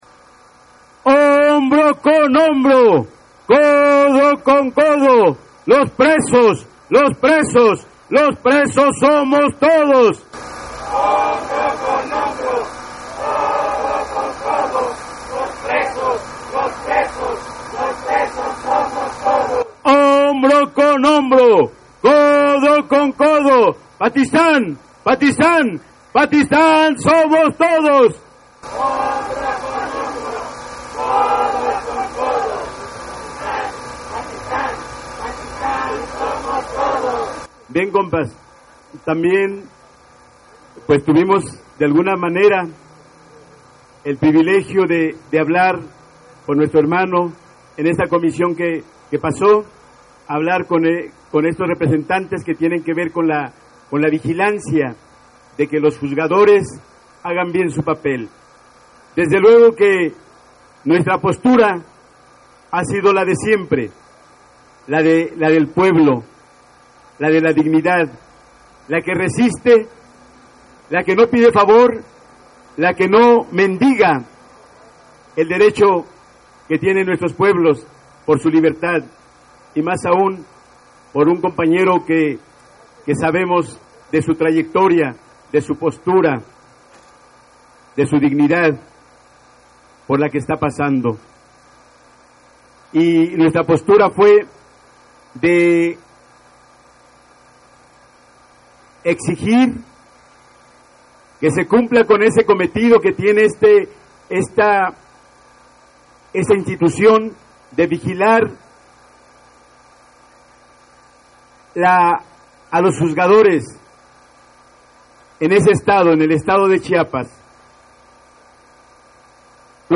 Desde muy temprana hora se congregaron aproximadamente 300 personas entre ellas compañerxs de la Sexta, organizaciones como la Coordinadora Nacional Plan de Ayala, medios libres, colectivos libertarios, entre otros.